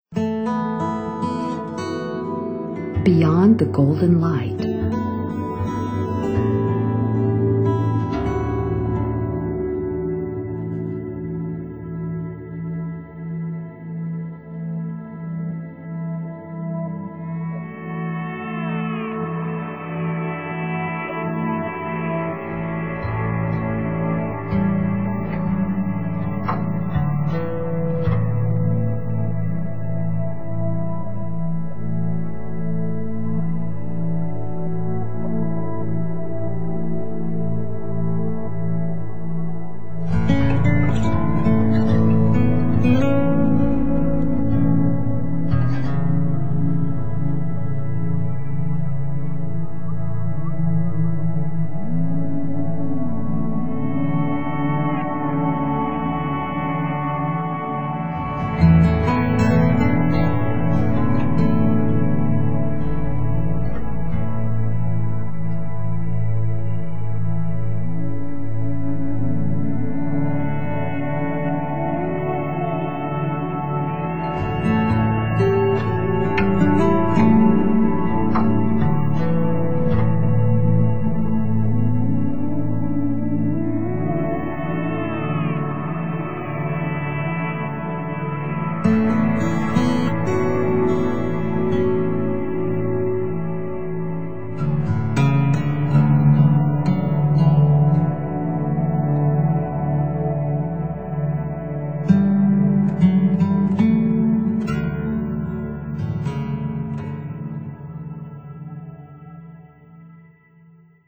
Relaxace, Meditace, Relaxační a Meditační hudba
Verbální vedení: Neverbální